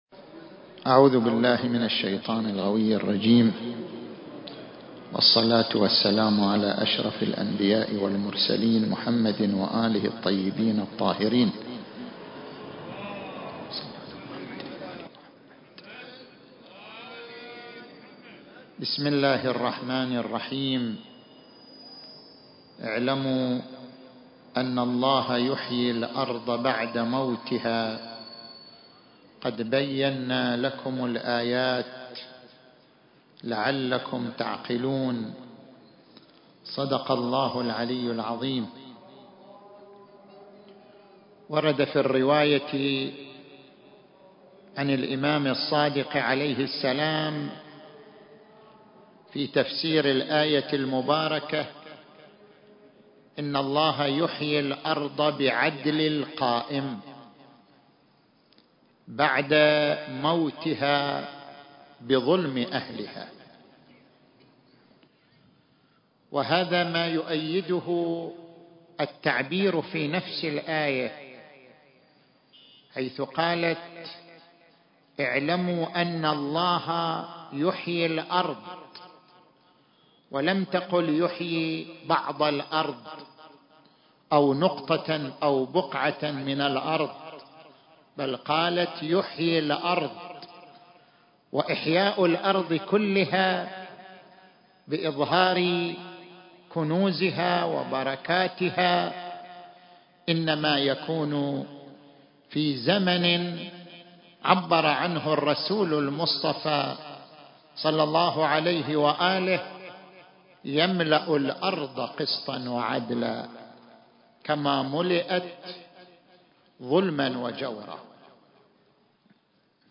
ذكرى مولد الامام المهدي المنتظر (عجّل الله فرجه) التاريخ: 1442 للهجرة المكان: مسجد المسألة - القطيف